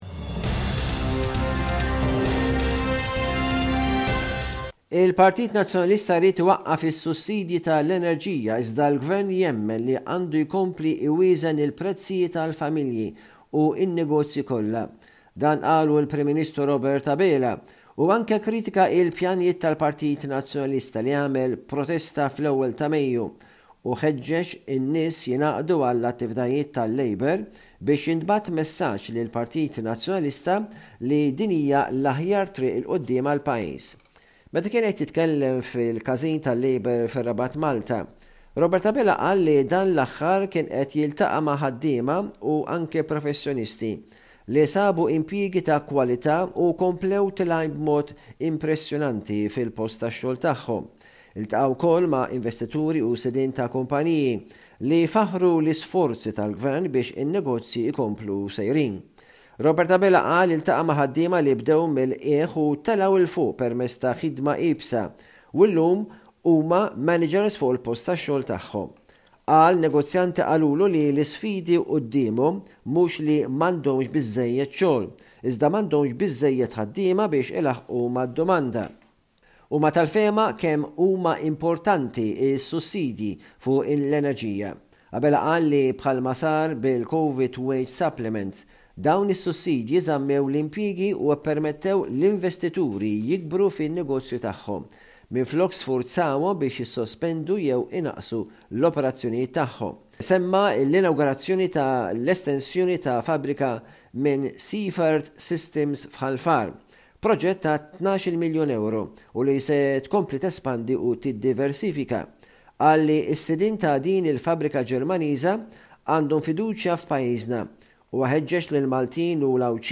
News report from Malta